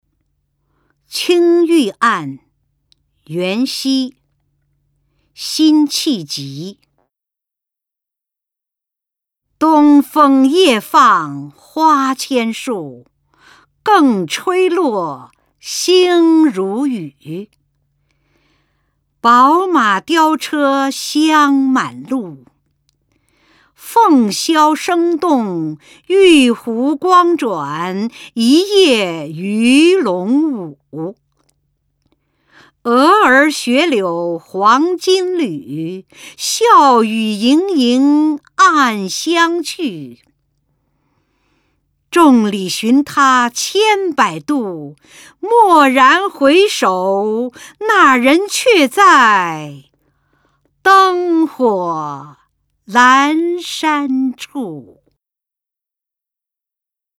原文誦讀錄音